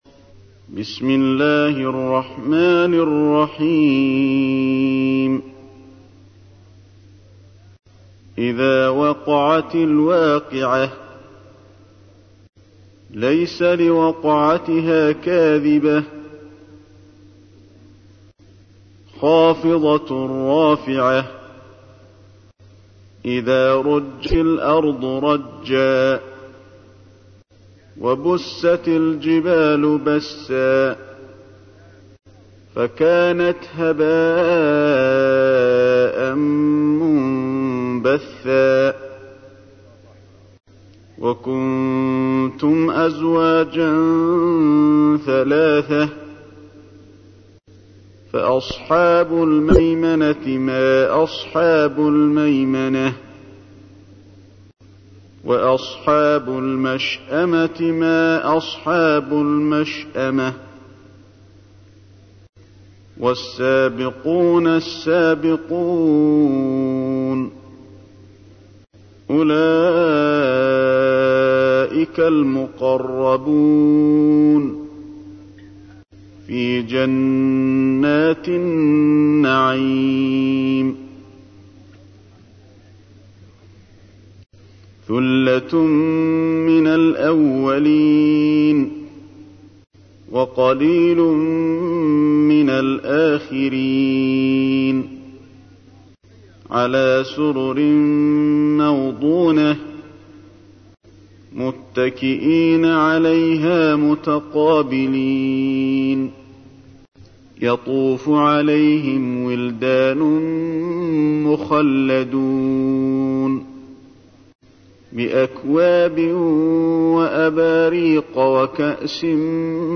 تحميل : 56. سورة الواقعة / القارئ علي الحذيفي / القرآن الكريم / موقع يا حسين